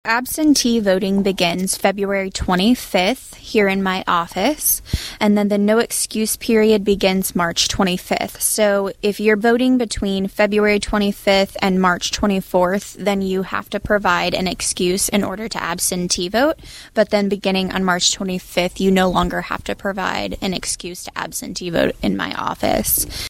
Absentee voting will begin next week for those that provide an excuse for not being able to make it to the polls in April. Saline County Clerk Brittni Burton has a rundown of the schedule for absentee voting.